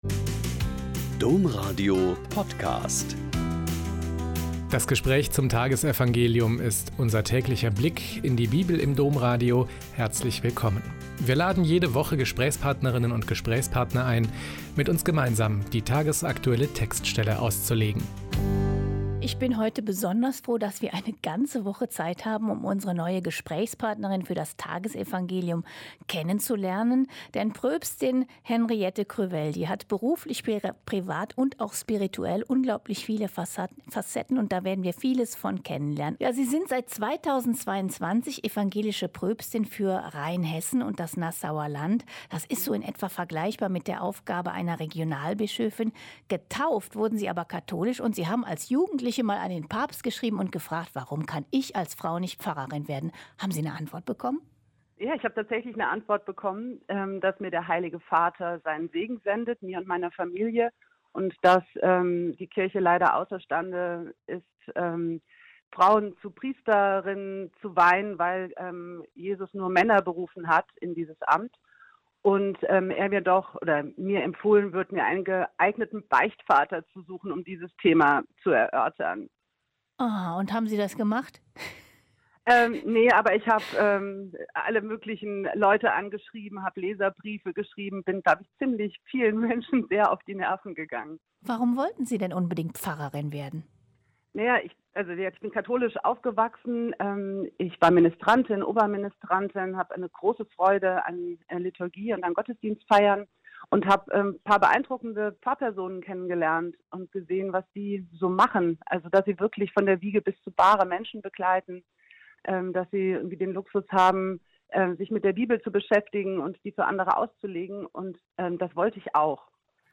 Mt 17,22-27 - Gespräch